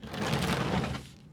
sfx_拖箱子.ogg